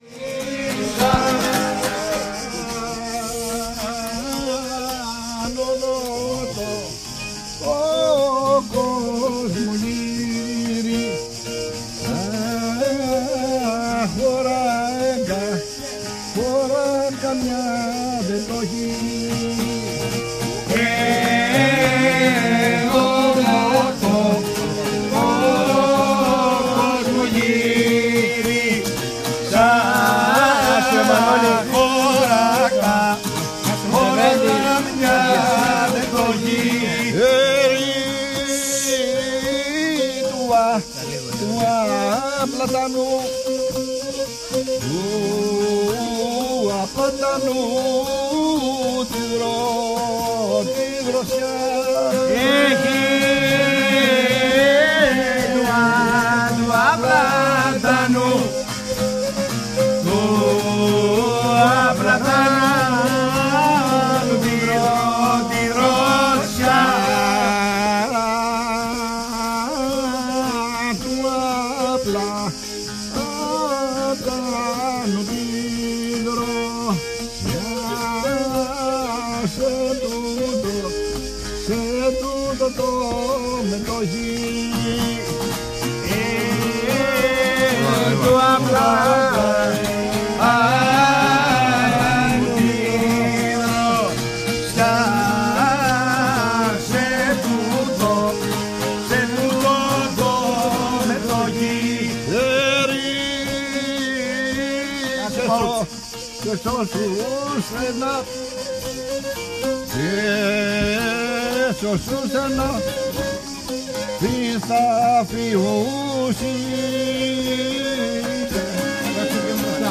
Περιγραφή:  Γλέντι κάτω από τον (α)πλάτανο, δίπλα στην κεντρική πηγή του Μερτώνα, καλοκαίρι του 2019.
Συνιστούμε τη χρήση ακουστικών για καλύτερη αίσθηση του στερεοφωνικού ήχου.
Περιοχή: Πλατεία πηγής Μερτώνα, Απερίου Καρπάθου.
Στοιχεία Ηχοληψίας: 2 channel 44Khz PCM/16 bit
Συσκευές: ZOOM D6 +  ZOOM XY
Mixdown: Audacity to 2 channel stereo
1. Ακούγονται τα τζιτζίκια σε όλη την ηχογράφηση.
2. Το 6ο μέρος ηχογραφήθηκε από συσκευή τηλεφώνου